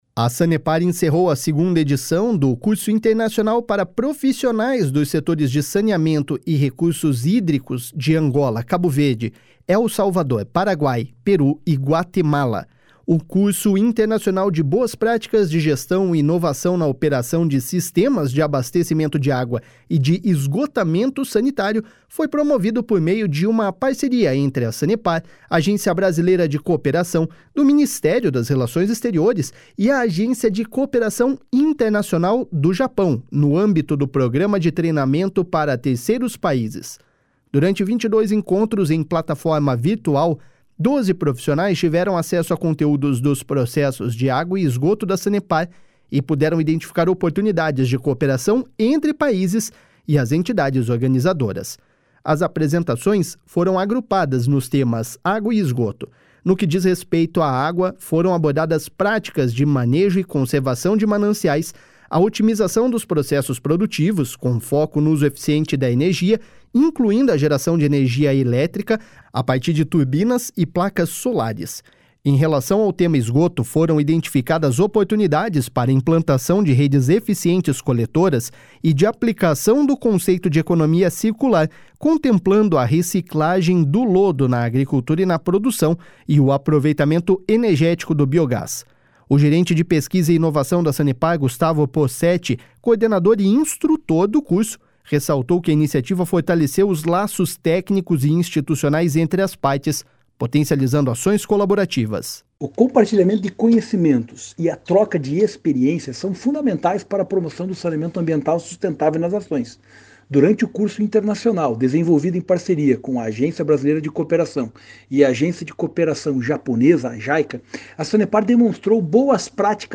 No encerramento, cada um apresentou de que forma os conhecimentos abordados no curso podem ser aplicados nos países, gerando aprimoramentos e valor para a sociedade. (Repórter